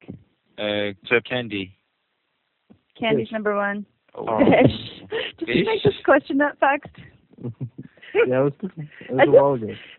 The following files are based on a four-person conversation (three male, one female) recorded over a PC-based conferencing test bed.
These files show the difference in speech quality between a conventional VoIP conference bridge using G.729A, and a Tandem-Free Operation conference with two selected speakers.
VoIP conference bridge with tandem connections using G.729A
Mixing-4of4-G729A.wav